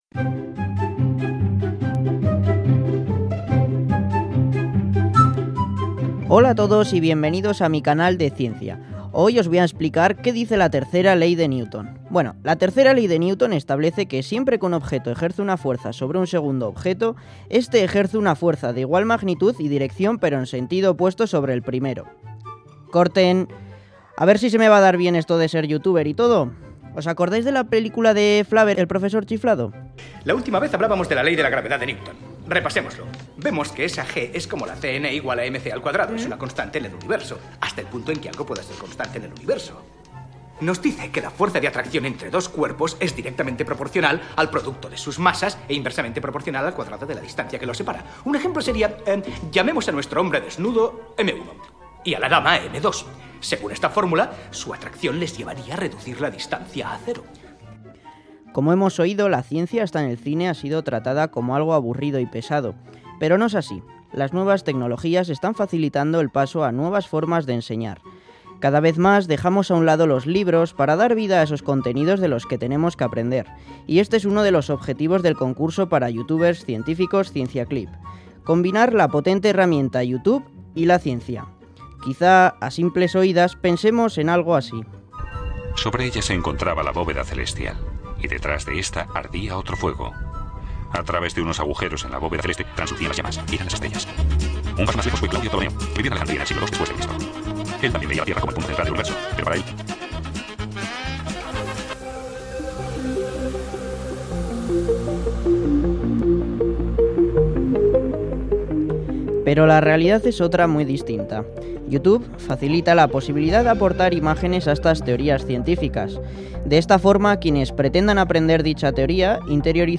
Audio: Reportaje: !Concurso Ciencia Clip!